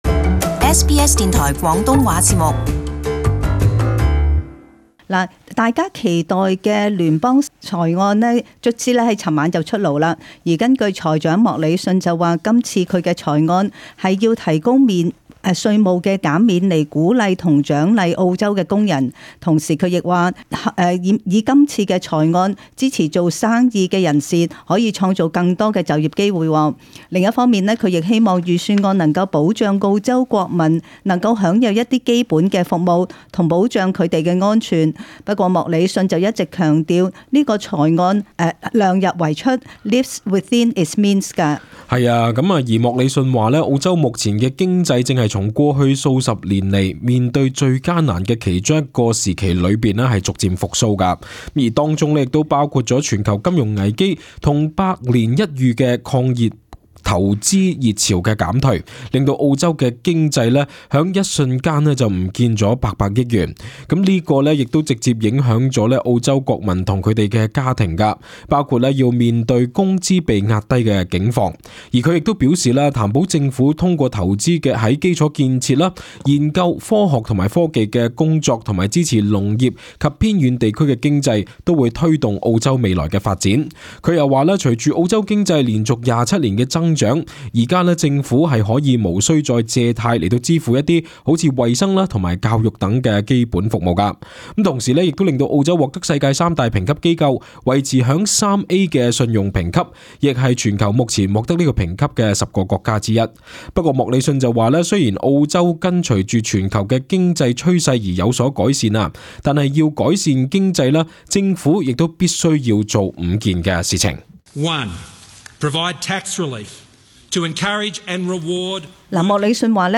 【時事報導】澳洲財長莫里遜昨晚公佈上任後第三份聯邦財政預算案